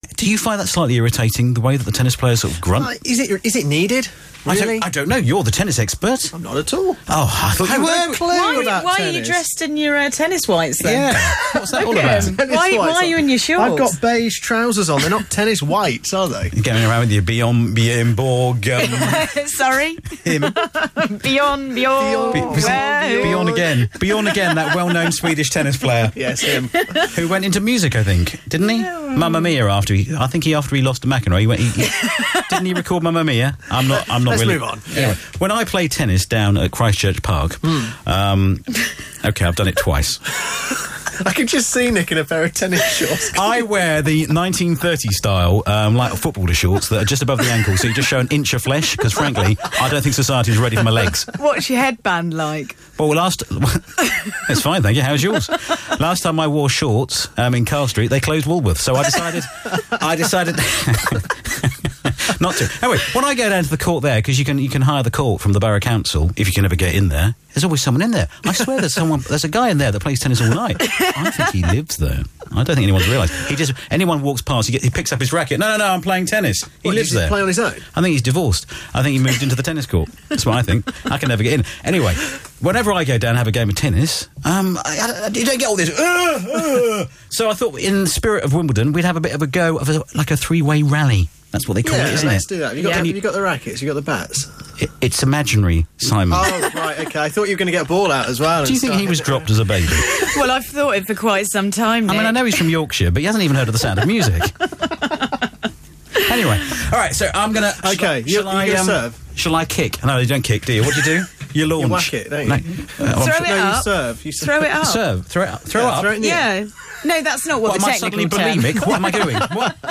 Town Breakfast does Tennis GRUNTS
had a GRUNTING match ........and then laughed.